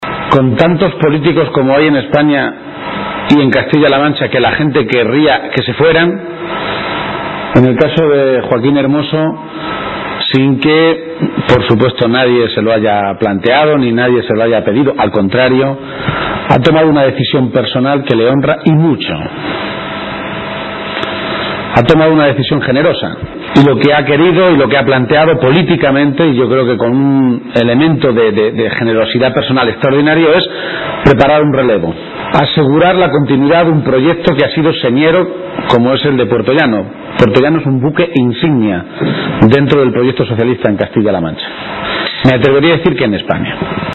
Unas palabras pronunciadas por García-Page en Puertollano, municipio al que ha acudido para participar en un acto informativo a la militancia organizado para dar detalles del proceso de cambio y renovación que se producirá en apenas dos semanas con el relevo en la Alcaldía que Joaquín Hermoso Murillo dará a Mayte Fernández.